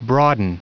Prononciation du mot broaden en anglais (fichier audio)
Prononciation du mot : broaden